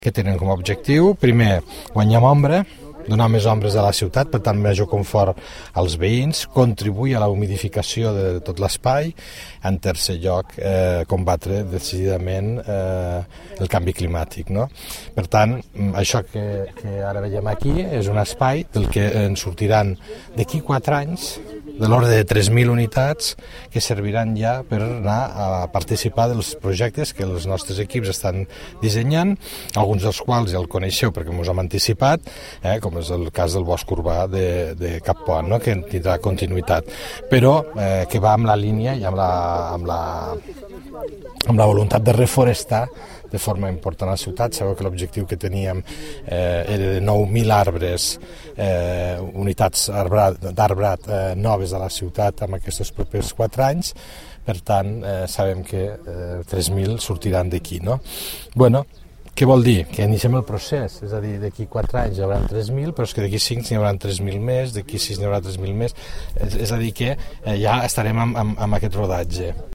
tall-de-veu-del-tinent-dalcalde-i-regidor-de-lhorta-felix-larrosa-sobre-el-viver-forestal-de-rufea